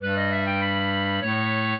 clarinet
minuet14-10.wav